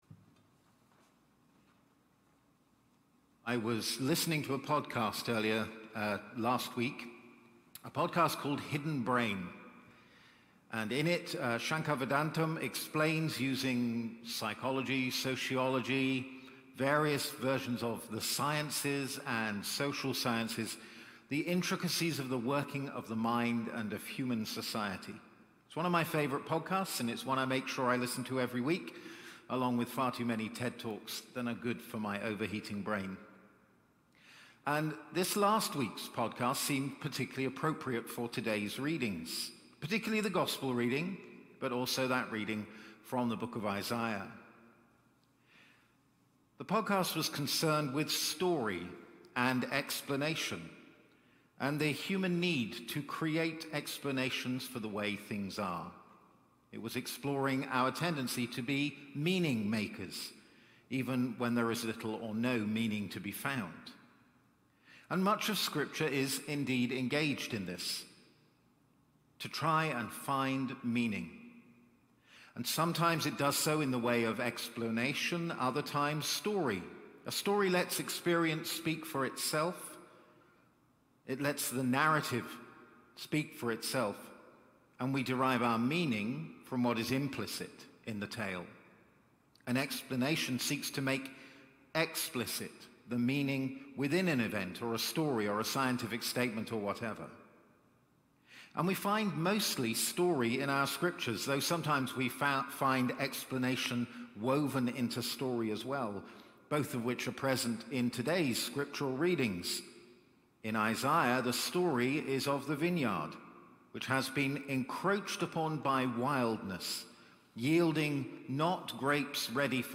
Palm Sunday Evensong Reflection